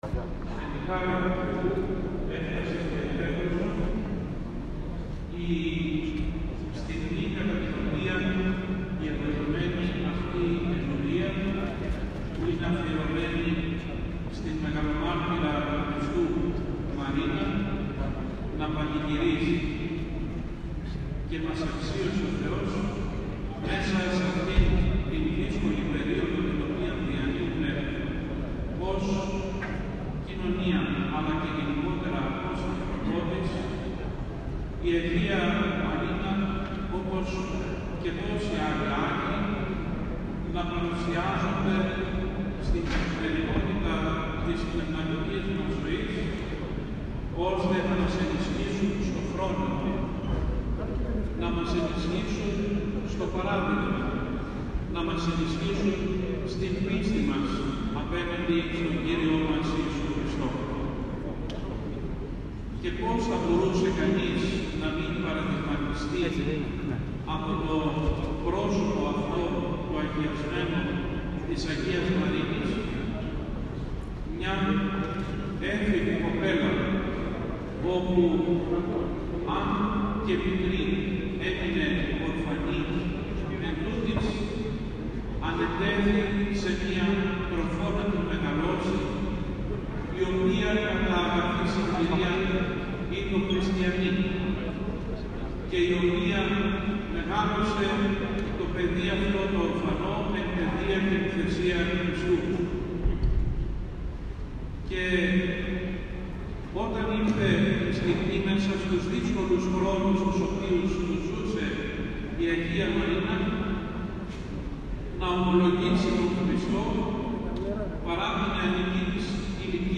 Την μνήμη της Αγίας Μαρίνας στην Άνω Τουμπα Θεσσαλονίκης εόρτασε σήμερα ο ομώνυμος περικαλλής Ιερός Ναός.